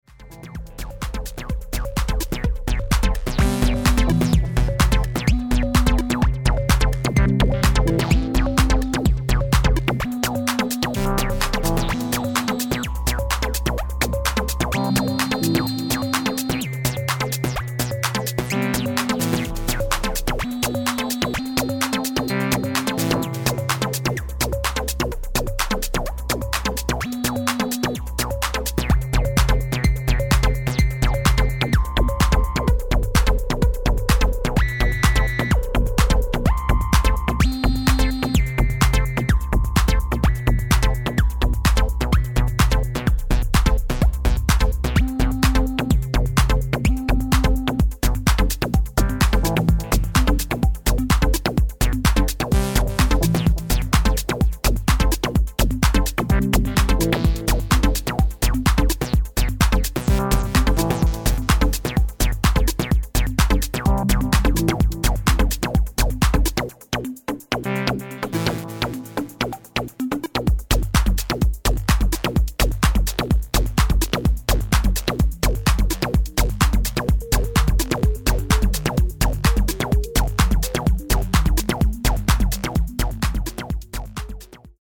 Ein digitaler Sound, analog umgesetzt.